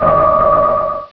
pokeemerald / sound / direct_sound_samples / cries / gardevoir.aif